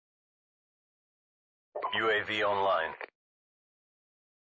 UAV Online Sound Effect Sound Effects Free Download